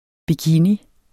Udtale [ biˈkini ]